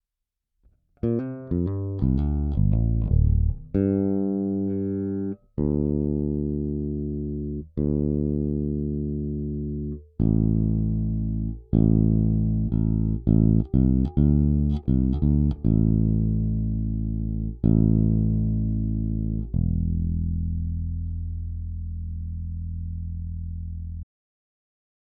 DRstruny problém